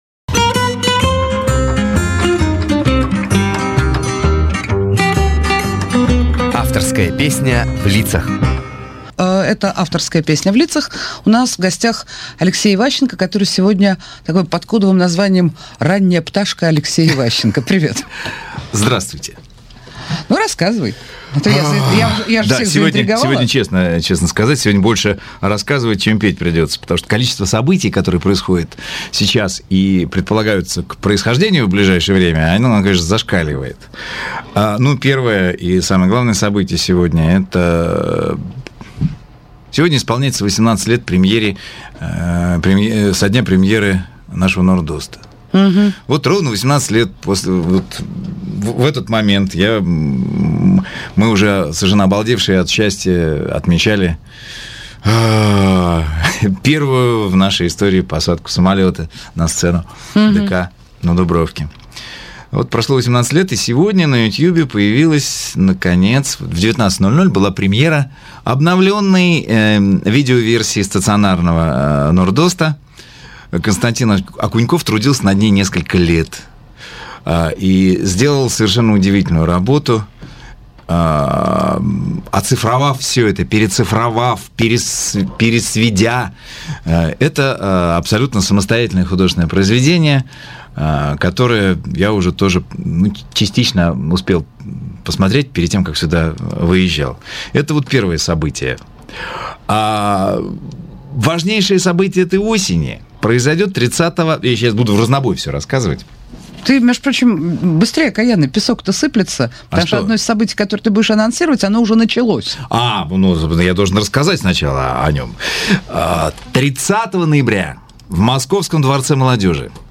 Актёр,автор - исполнитель,певец
Жанр: авторская песня